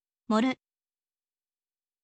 moru